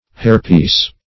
hairpiece \hair"piece`\ n.